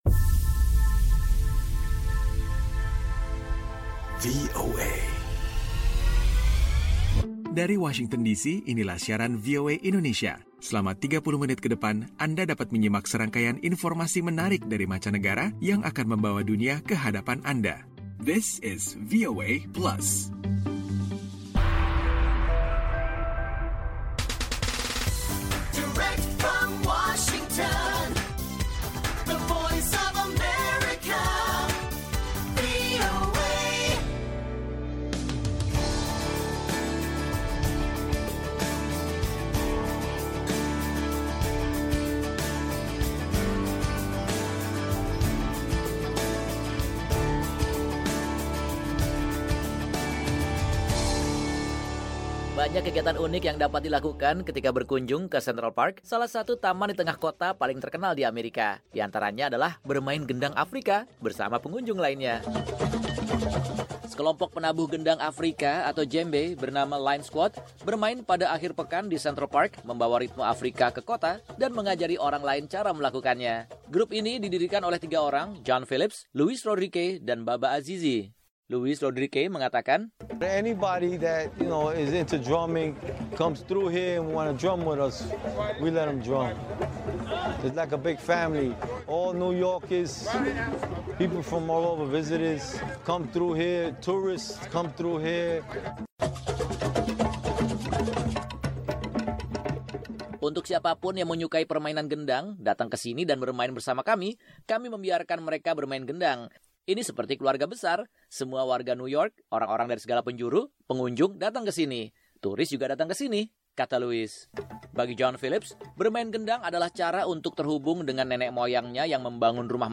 VOA Plus kali ini akan mengajak anda menyimak permainan gendang dengan ritme musik Afrika di tengah taman kota New York, Central Park. Ada pula sederet info dan tips untuk bisa menembus industri kreatif internasional dari sejumlah pekerja seni Indonesia di mancanegara.